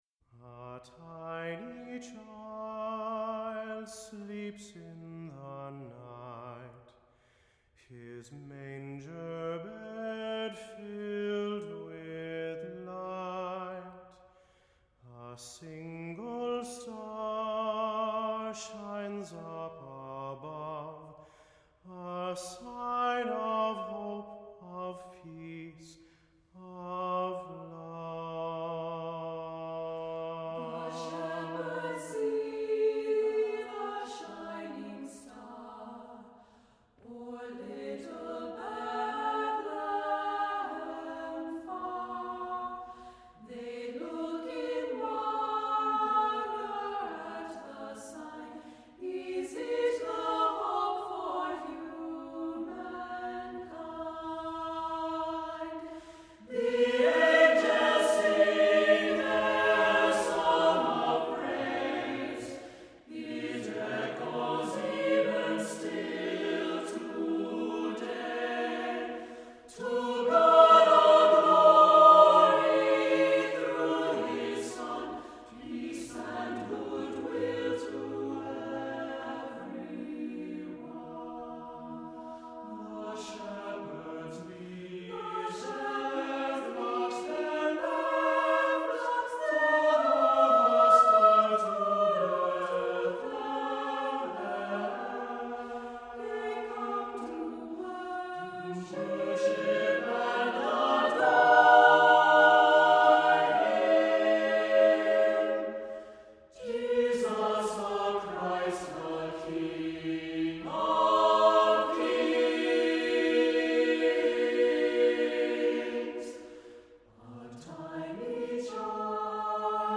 Music Category:      Choral